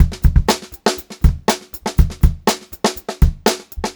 Index of /90_sSampleCDs/USB Soundscan vol.38 - Funk-Groove Drumloops [AKAI] 1CD/Partition C/06-121FUNKY